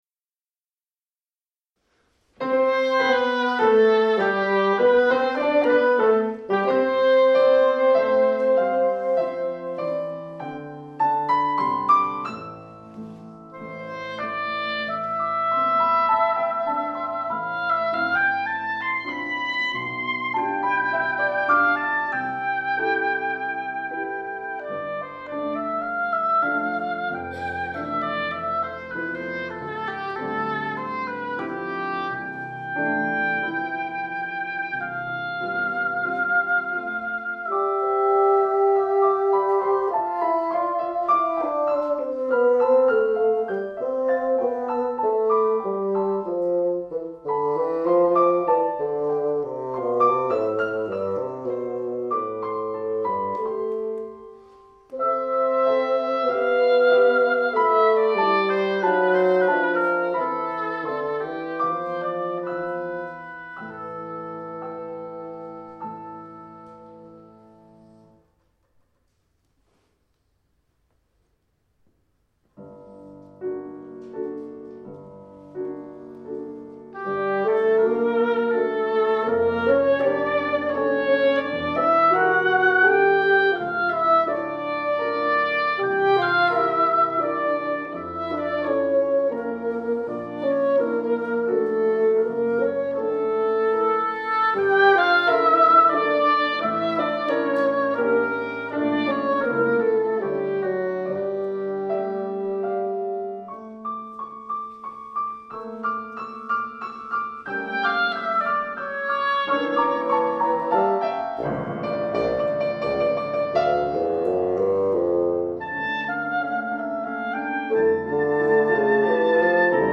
Zurich Opera House, Foyer Concert
for oboe, bassoon & piano
Live performance, Jan 13, 2008, mp3 file)